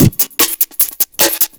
ELECTRO 04-L.wav